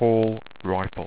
Pronounced
PORL RIFEL
REIFFEL_PR.au